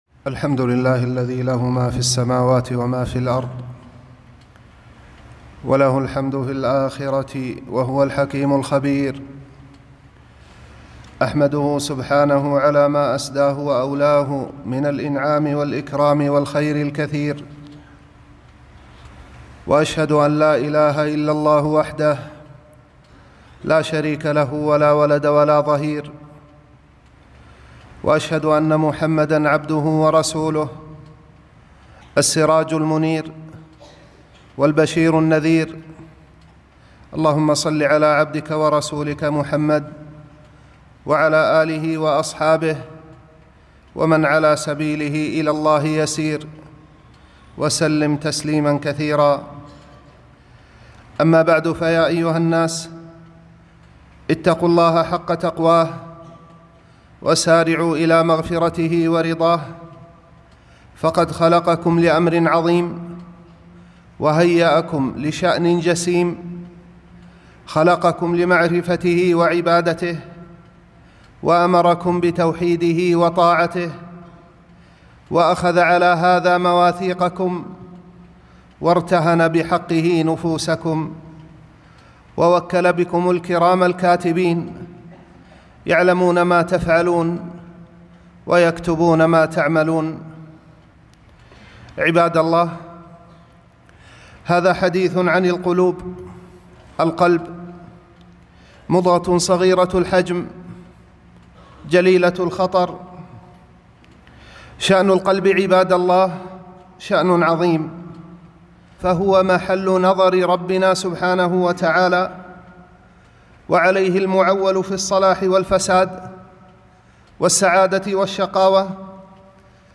خطبة - حديثٌ عنِ القــلوب